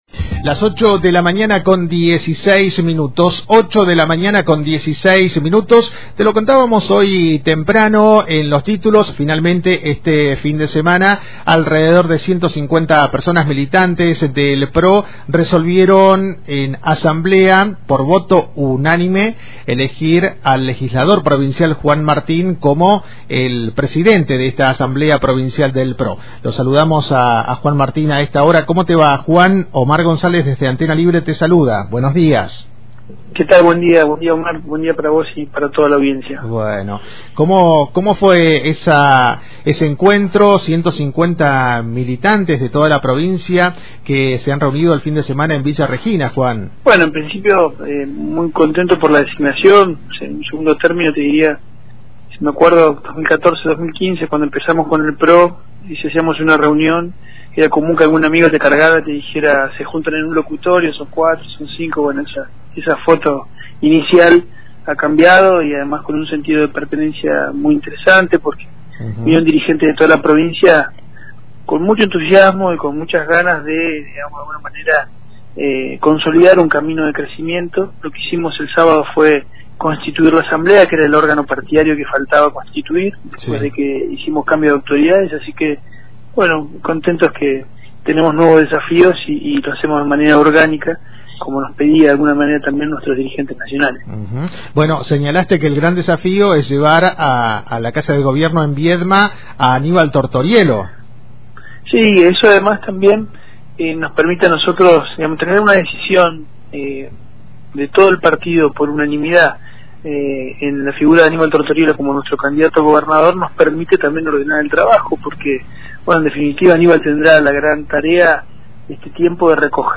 En diálogo con Antena Libre, comentó cuáles son sus principales objetivos y evaluó la participación juvenil en el partido.